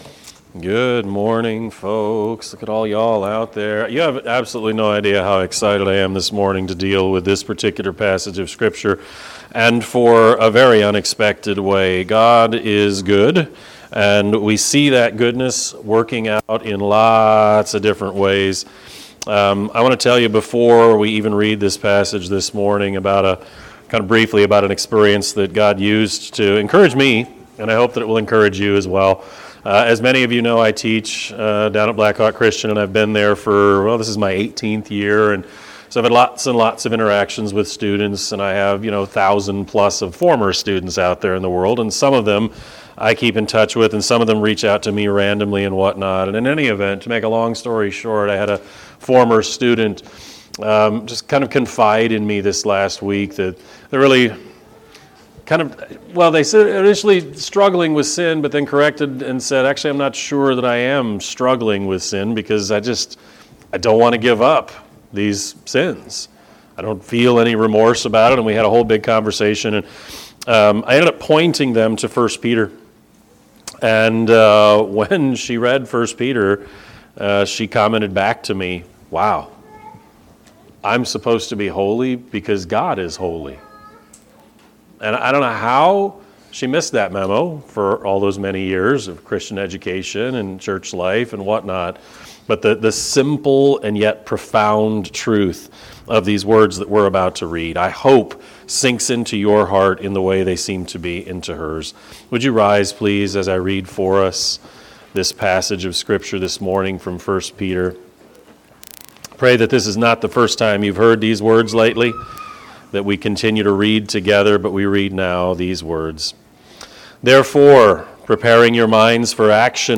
Sermon-4-12-26-Edit.mp3